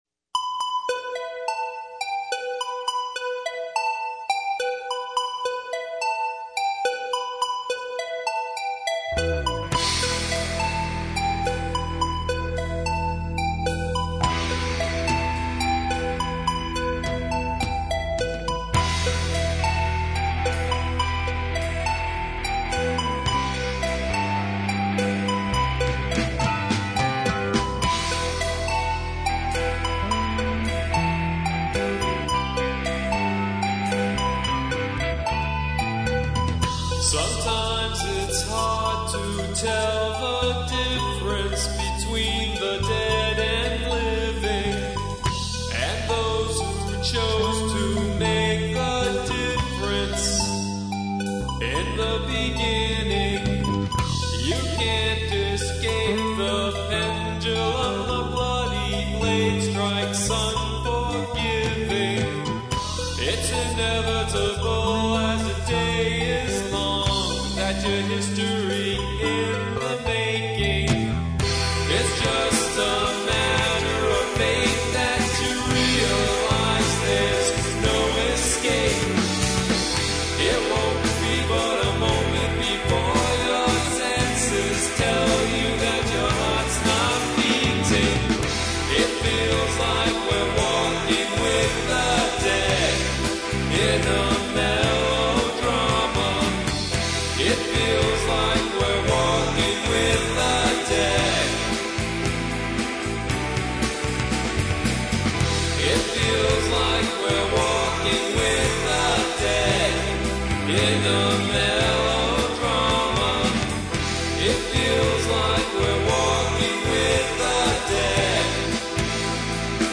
Keyboards, Vocals
Drums, Vocals
Bass, Vocals
Guitar, Lead Vocals